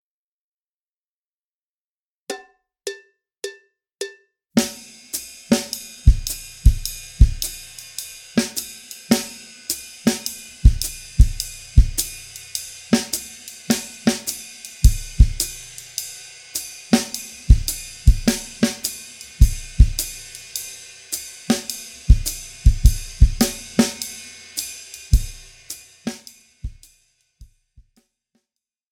Besetzung: Schlagzeug
09 - Comping 2